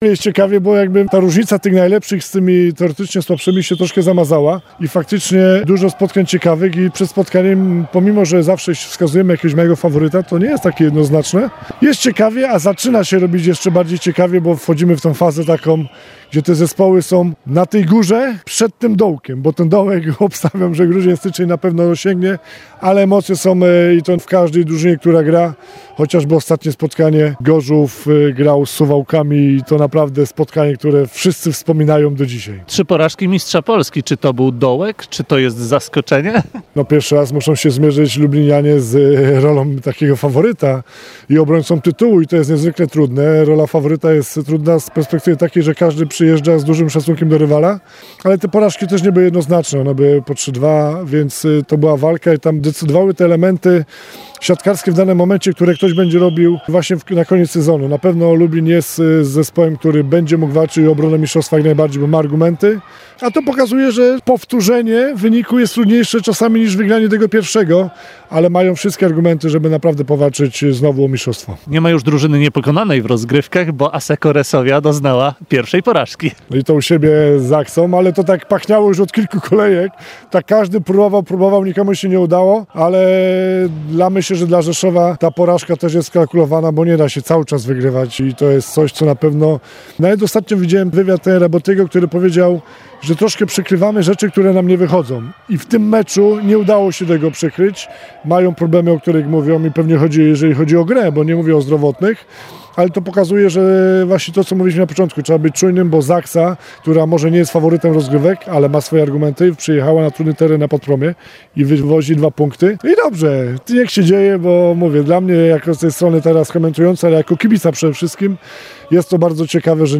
– Początek ligi jest szalenie interesujący i ciężko wskazać faworyta do złotego medalu – mówi były znakomity reprezentant Polski, a obecnie ekspert telewizyjny Piotr Gruszka.